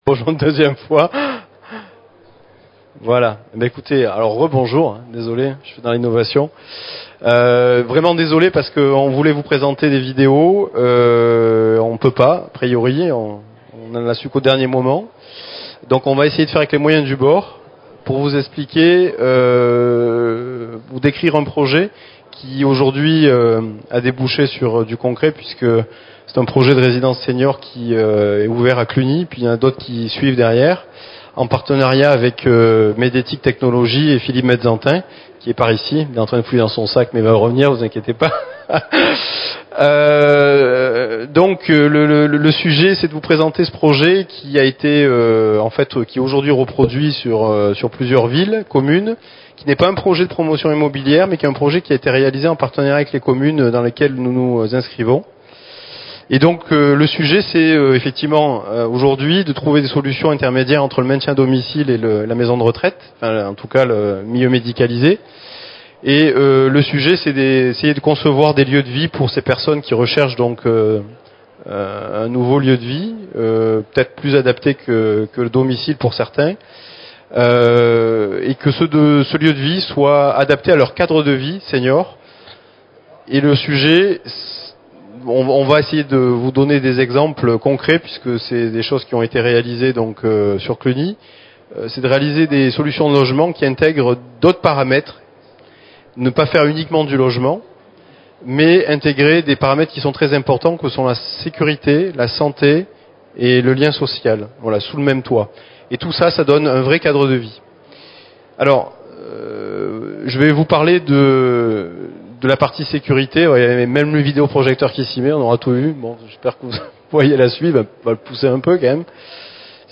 Résidences pour seniors : il faut faire plus que du logement ! Conférence Silver Economy Expo 2013